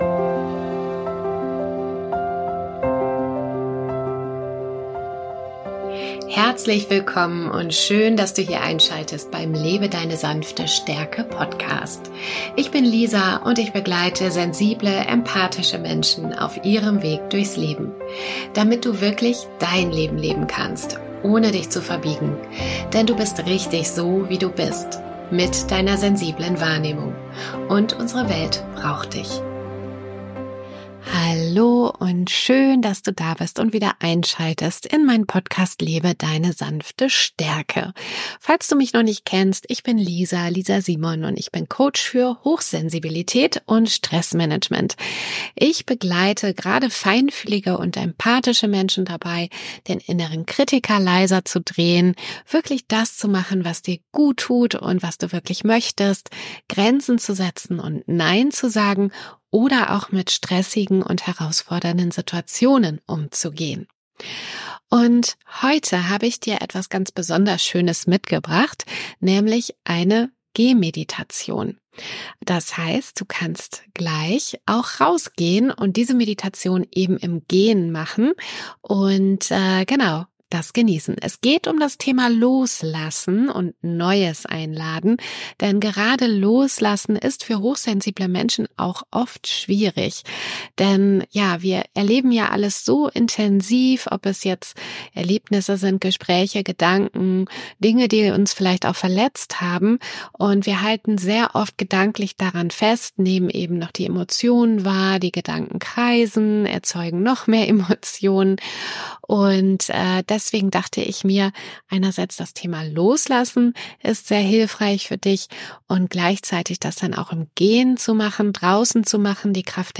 - Eine angeleitete Gehmeditation für mehr Leichtigkeit und innere Klarheit